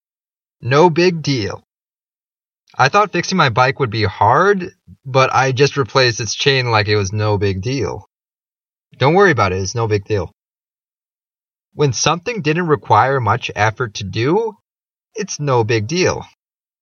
英語ネイティブによる発音は下記のリンクをクリックしてください。
nobigdeal.mp3